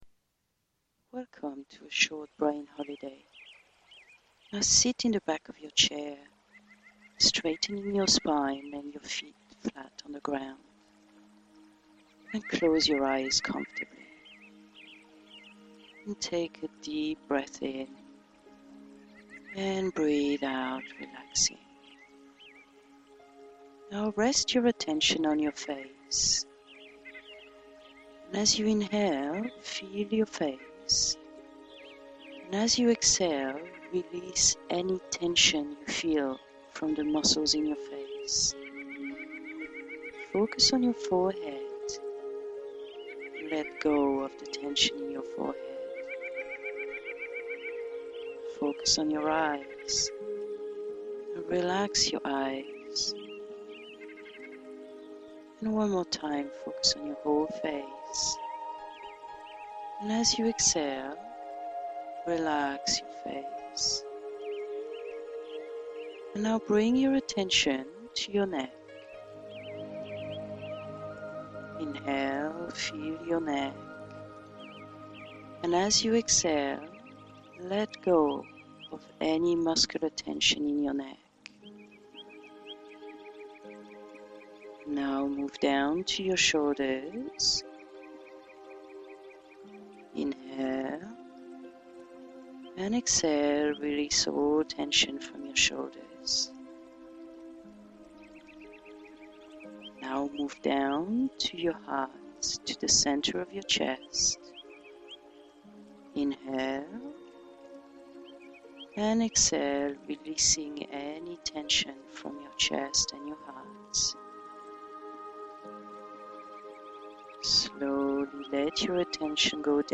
A 5min relaxation to recharge brains on high demand!